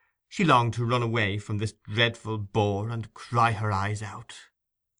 Interestingly, the same pattern applies to certain idiomatic constructions which, without being proper phrasal verbs, are similarly built.
134.-cry-her-EYES-out.wav